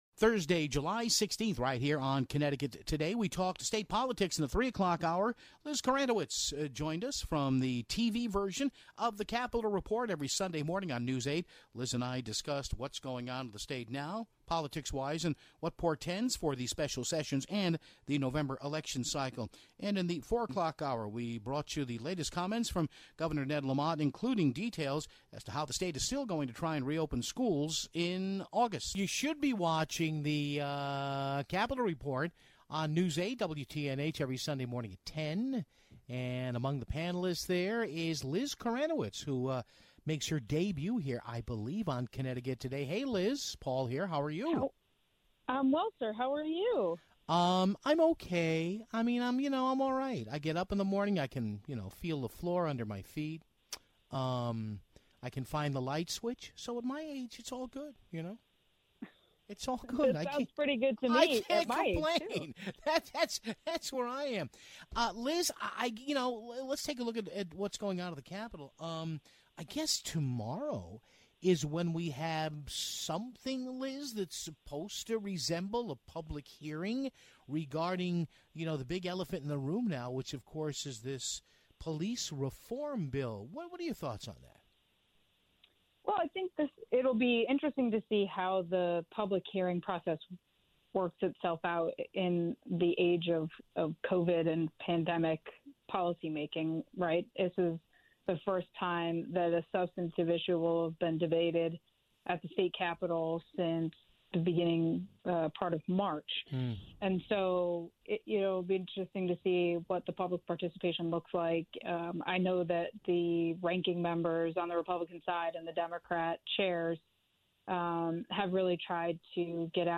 We also carried the Government Ned Lamont's press conference.